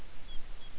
I have two recordings of the same bird call event, from two different songmeter SM3s that were 75m apart - i.e. a bird called, and the call was picked up simultaneously on two different machines.
. The call has two syllables, and the clips capture both syllables.